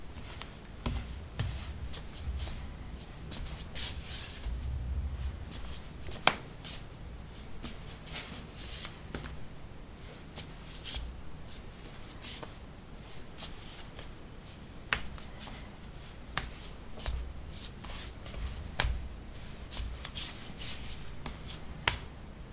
Footsteps carpet » carpet 06c ballerinas run
描述：Running on carpet: ballerinas. Recorded with a ZOOM H2 in a basement of a house, normalized with Audacity.
标签： footsteps footstep steps
声道立体声